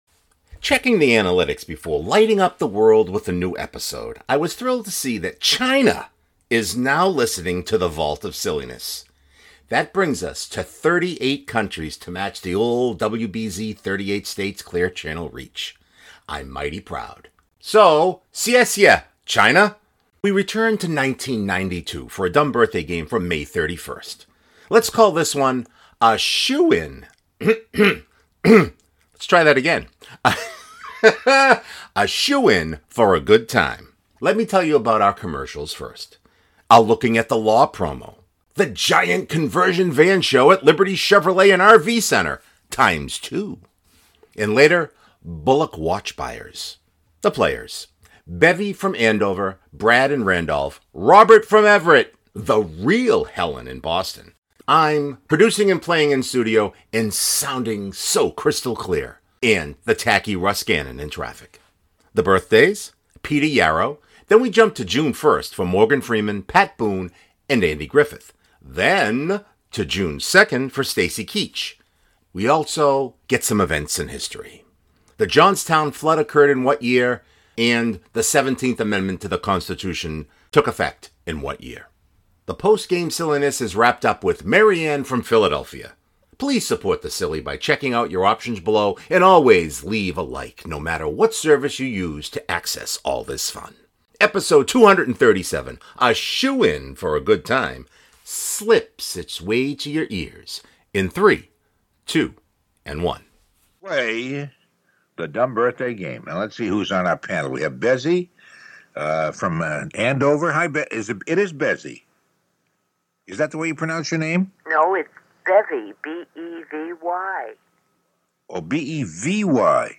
That brings us to 38 countries to match the old WBZ 38 states clear channel reach.